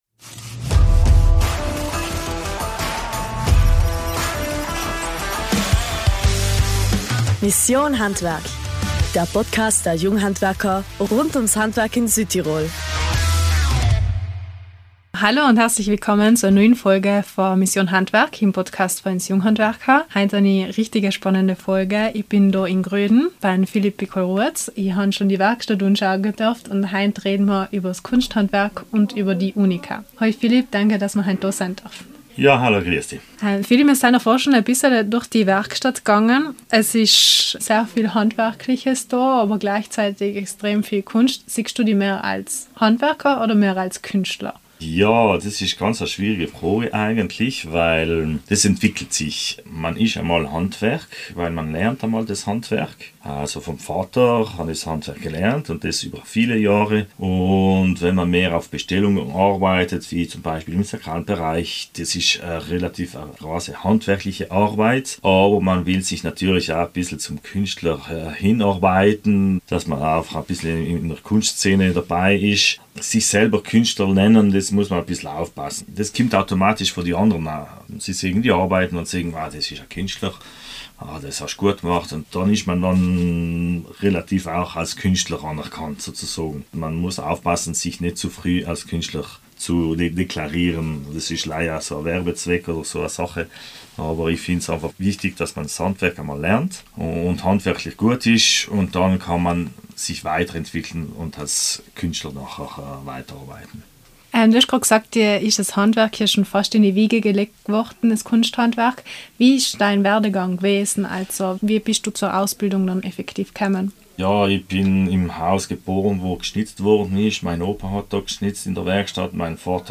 Couchratscher